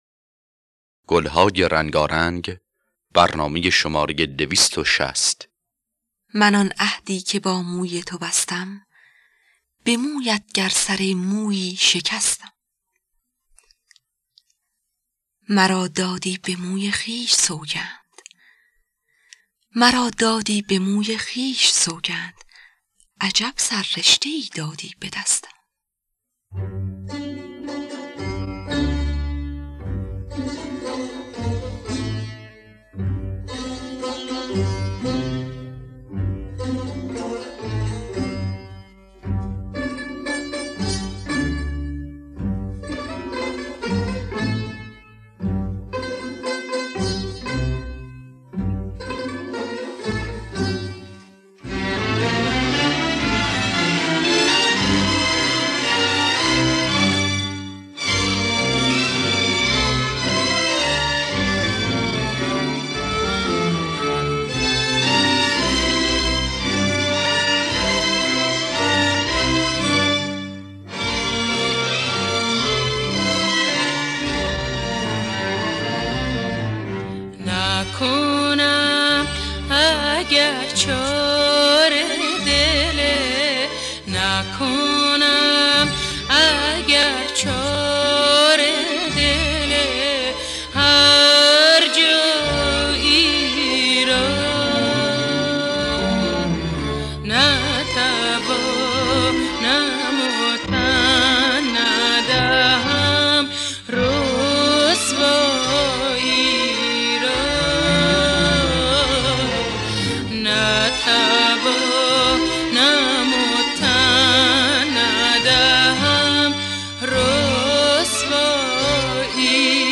در دستگاه افشاری